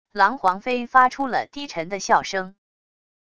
狼皇妃发出了低沉的笑声wav音频